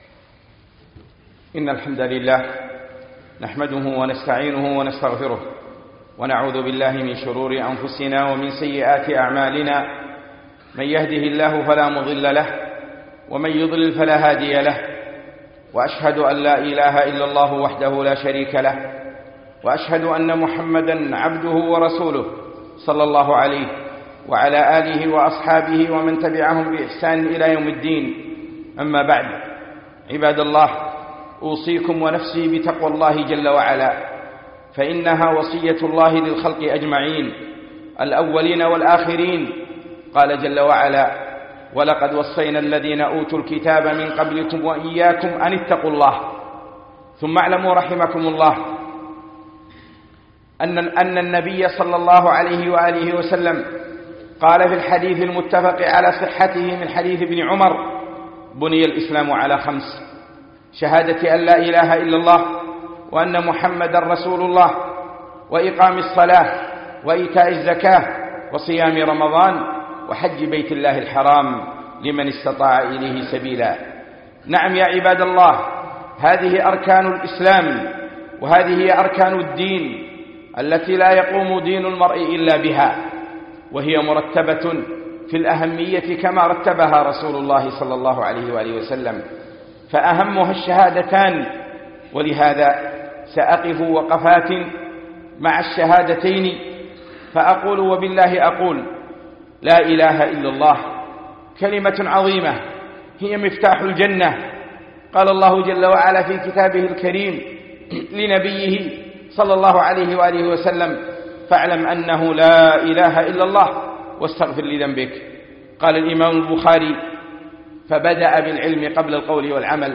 خطبة وقفات مع الشهادتين